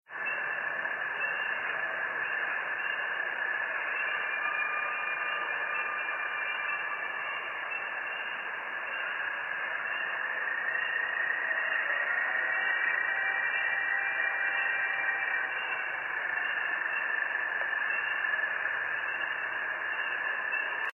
Category: Animals/Nature   Right: Personal
Tags: Wildlife audio recordings Unknow Wildlife Souns